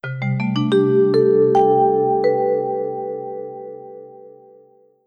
startup.wav